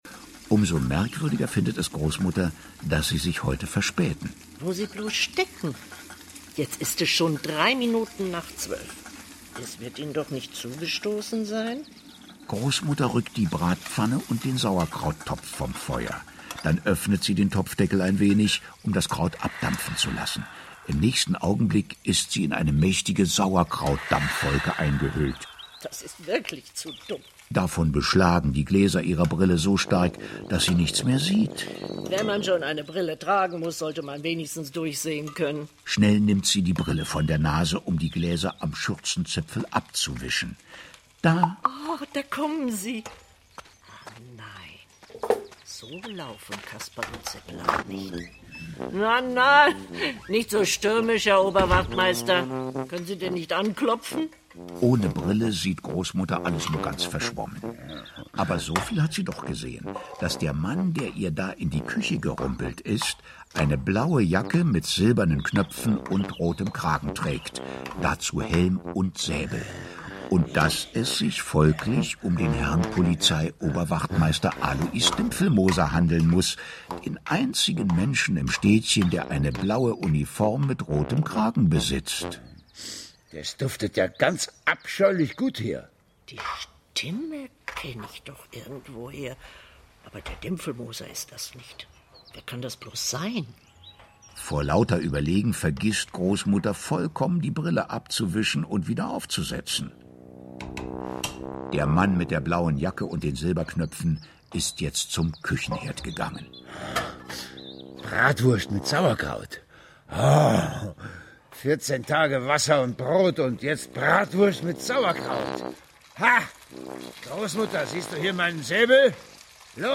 Hörspiel (2 CDs)
Michael Mendl, Dustin Semmelrogge (Sprecher)
Eine liebevoll inszenierte Räubergeschichte des WDR, in der neben anderen Michael Mendl als Räuber Hotzenplotz und Dustin Semmelrogge als Seppel begeistern.